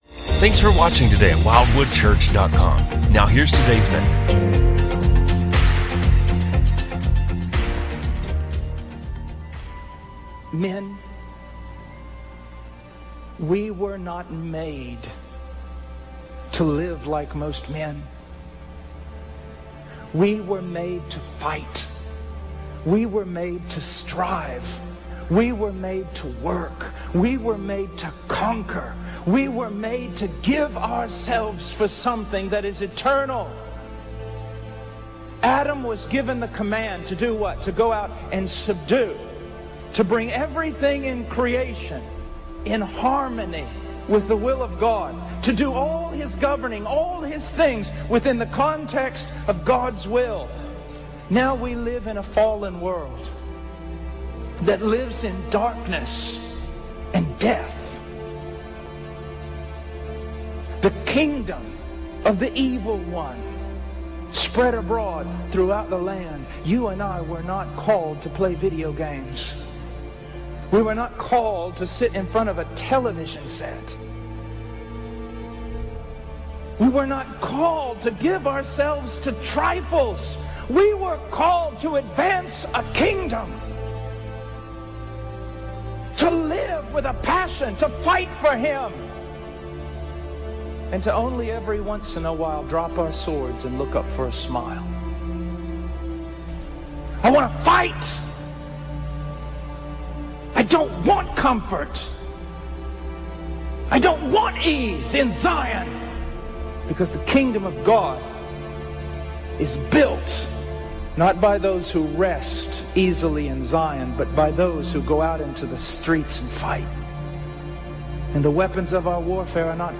A message from the series "Stand Alone Sermons."